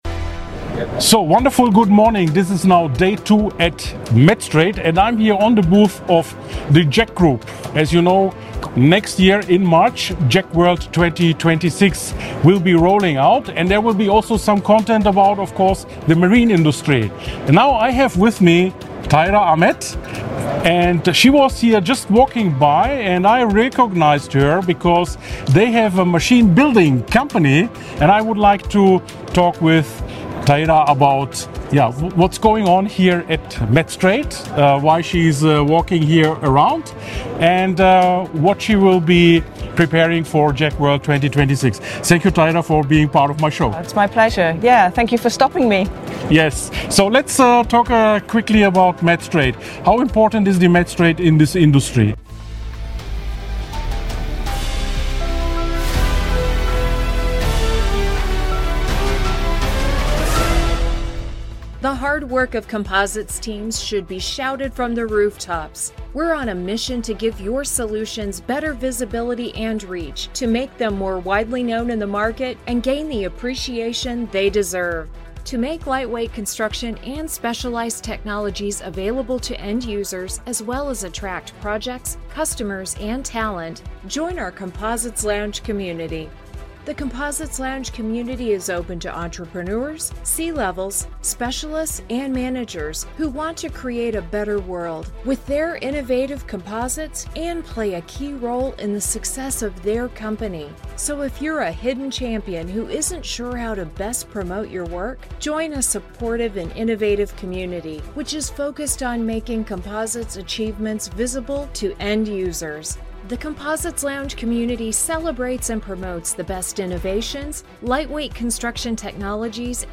Beschreibung vor 2 Monaten BREAKING | Manufacturing Innovation in Marine & Composites At Metstrade Amsterdam, on the JEC Group booth, a quiet but important announcement was made.